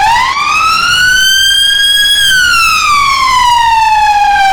Index of /server/sound/vehicles/lwcars/sfx/sirens
uk_wail.wav